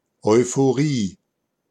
Ääntäminen
IPA: /ɔɪ̯foˈʀiː/